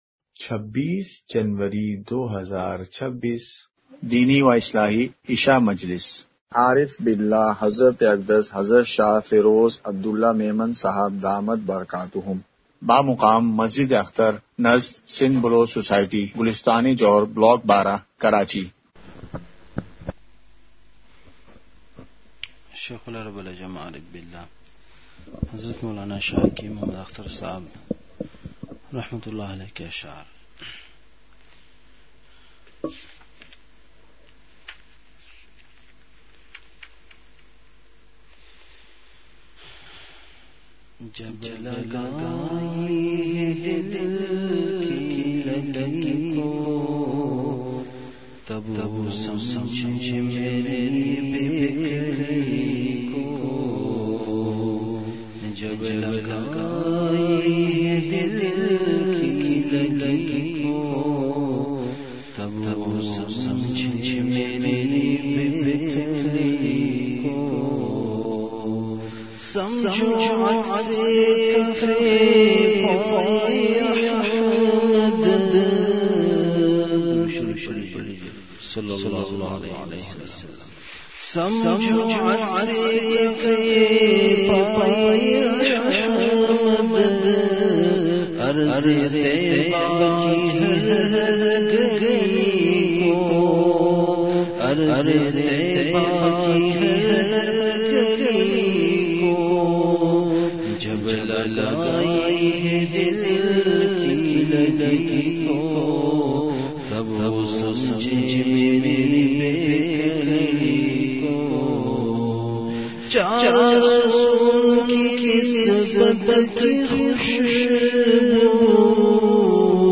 *مقام:مسجد اختر نزد سندھ بلوچ سوسائٹی گلستانِ جوہر کراچی*